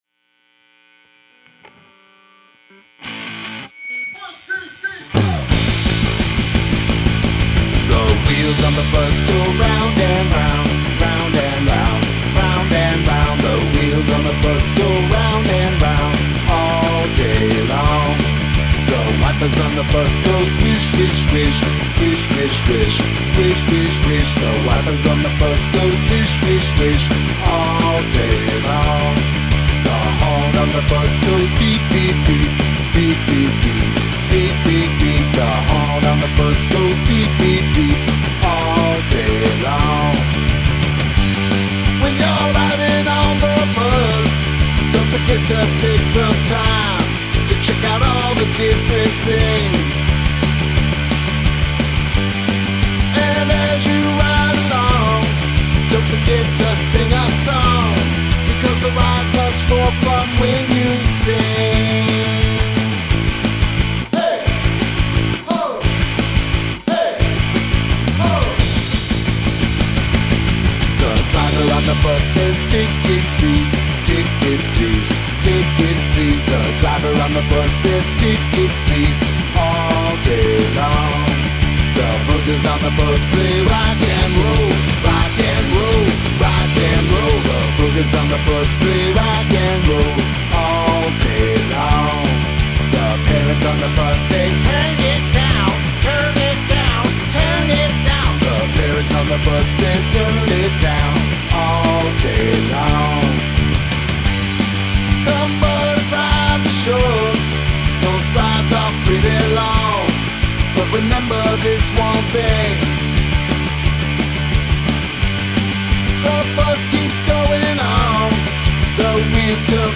alone...Lots of short, fun, loud songs ... perfect for your